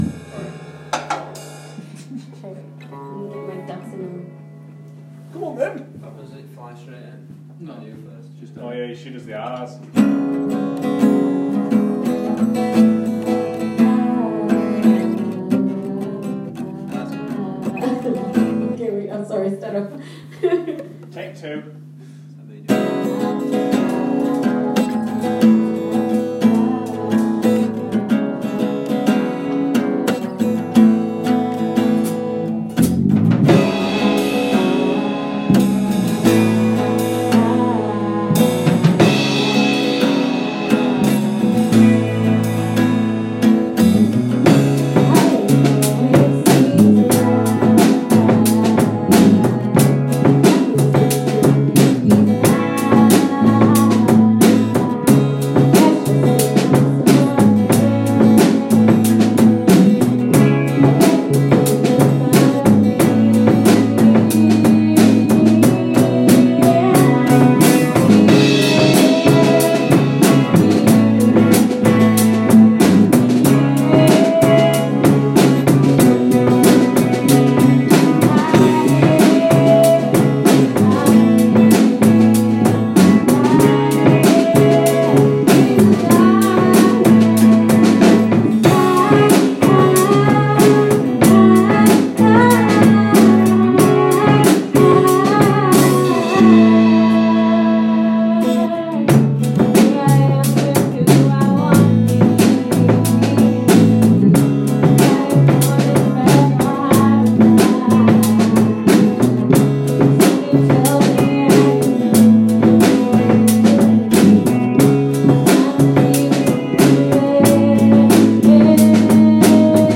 Feel-it-BAND.m4a